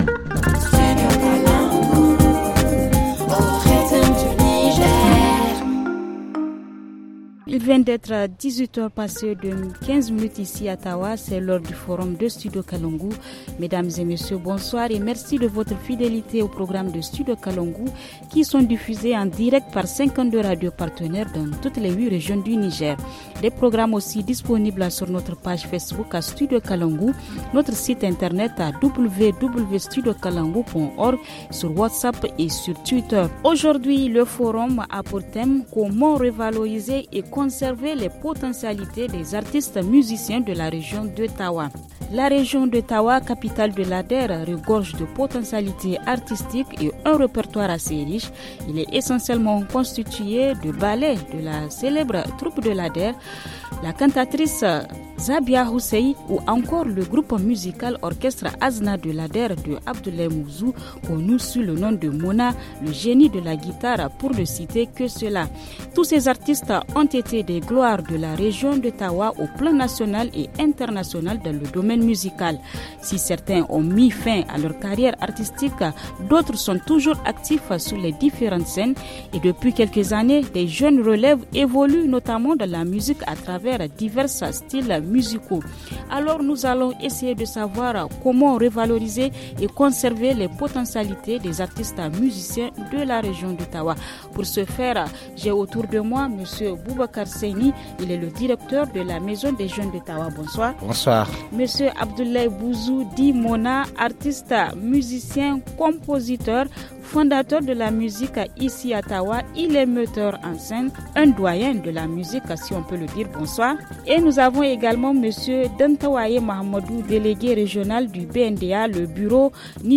Dans ce débat, nous allons essayer de savoir comment revaloriser et conserver les potentialités des artistes musiciens de la région de Tahoua.